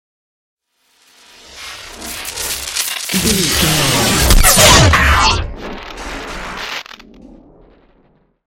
Whoosh to hit electricity large
Sound Effects
dark
futuristic
intense
woosh to hit